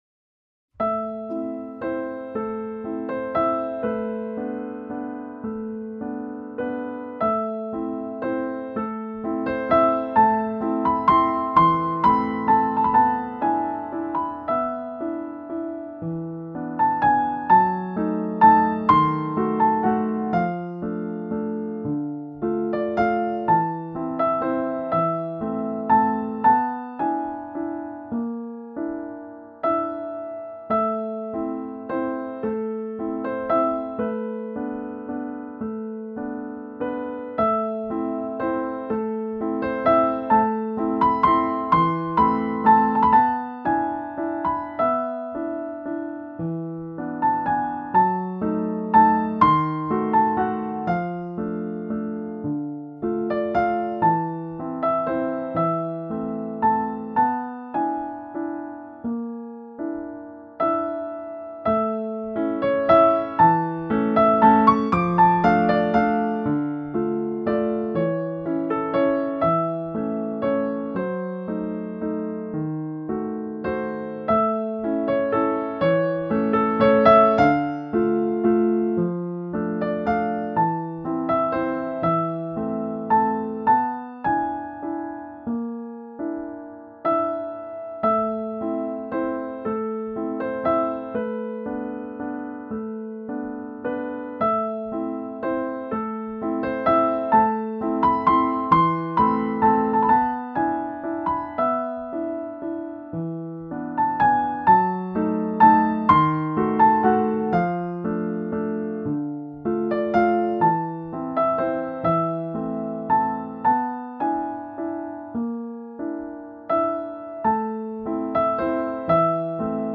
Piano solo